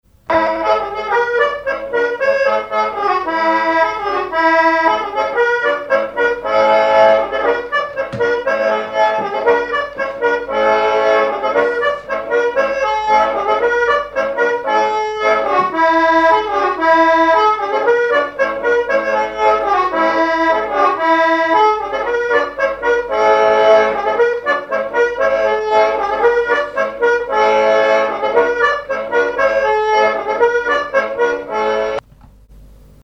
branle
Chants brefs - A danser
accordéoniste
Pièce musicale inédite